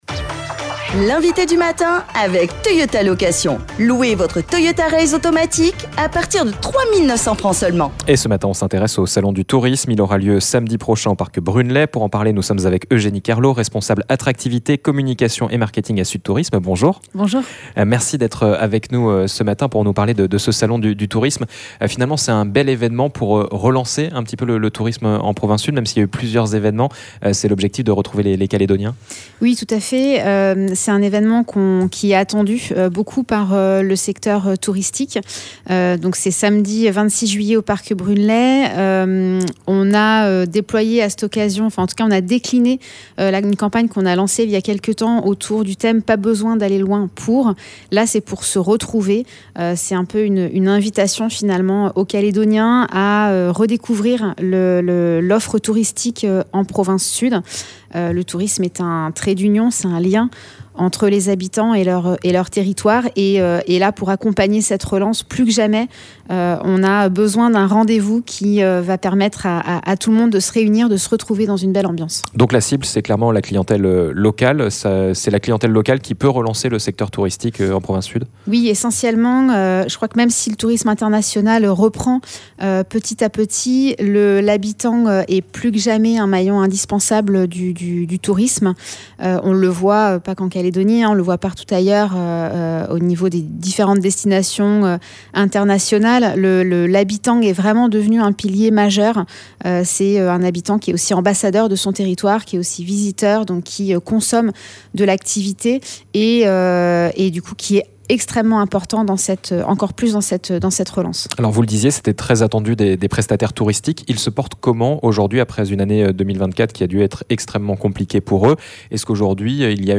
Elle était notre invitée du matin à 7h30. L’occasion également de dresser un état des lieux du tourisme en Province Sud.